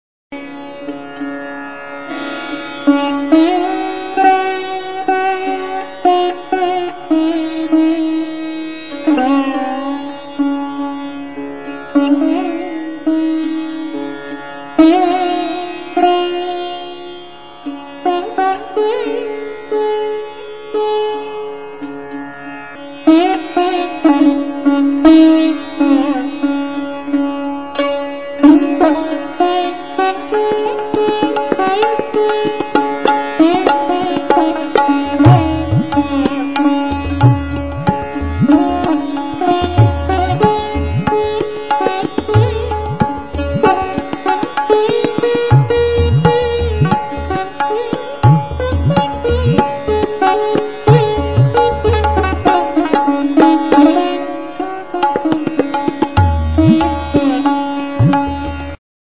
Stringed -> Plucked